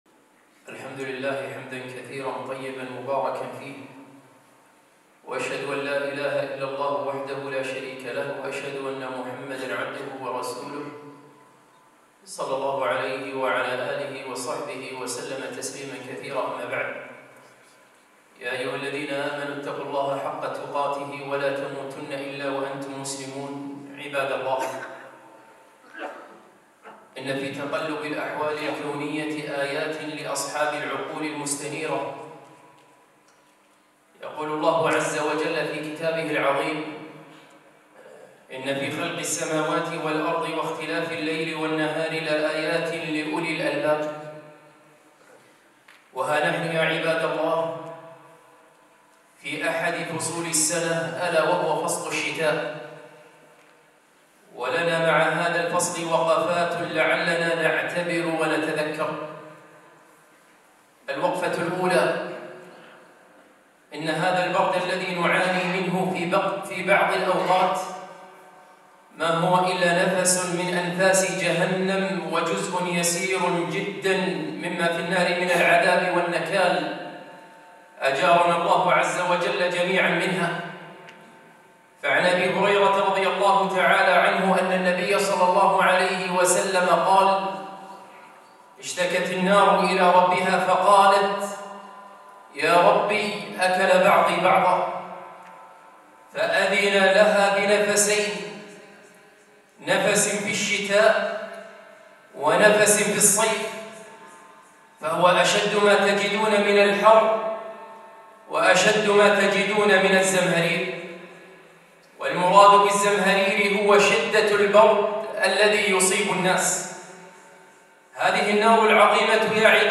خطبة - ها قد دخل فصل الشتاء